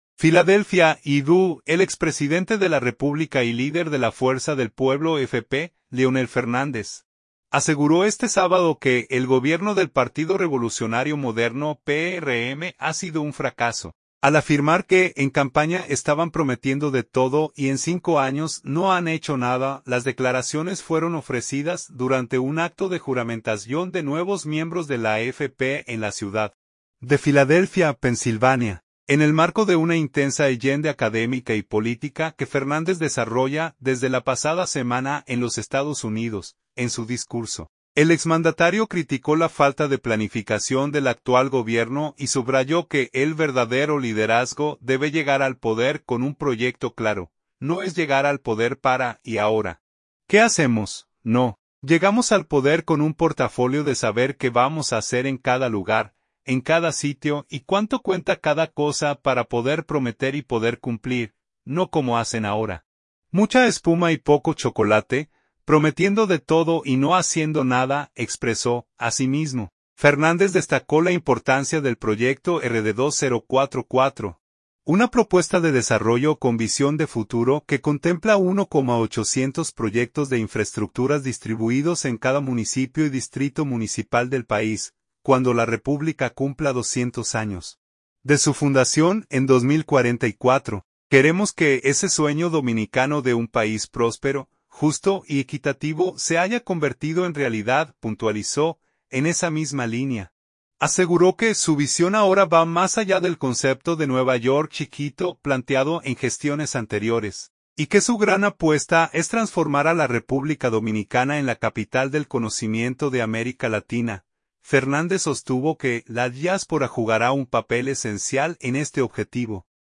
Las declaraciones fueron ofrecidas durante un acto de juramentación de nuevos miembros de la FP en la ciudad de Filadelfia, Pensilvania, en el marco de una intensa agenda académica y política que Fernández desarrolla desde la pasada semana en los Estados Unidos.